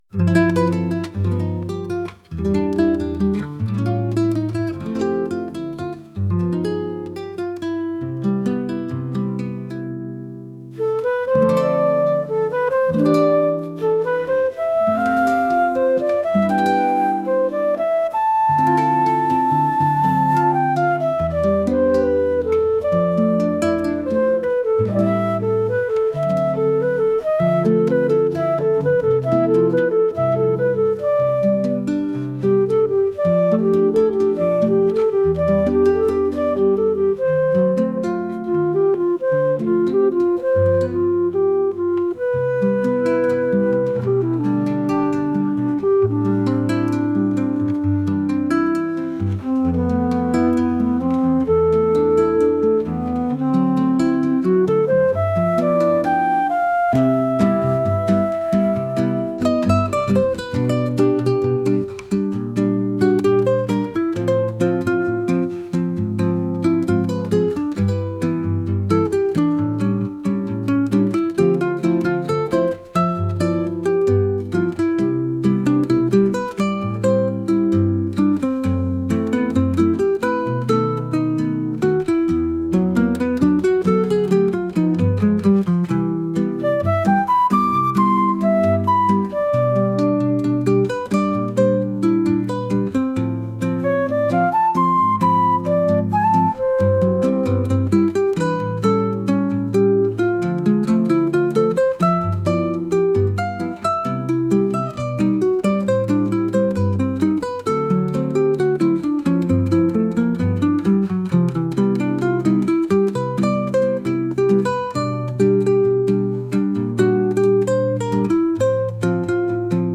一日の終わりを感じるようなゆったりしたボサノバ曲です。